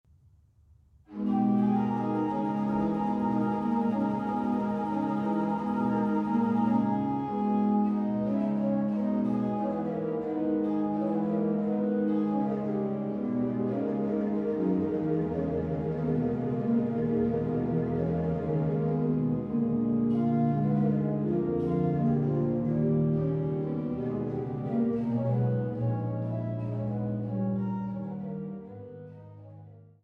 gespielt an der Trost-Orgel der Schlosskirche Altenburg